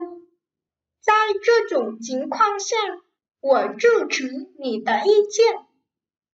Chai trưa trủng chính khoang xa, gủa trư chứ nỉ tơ i chen.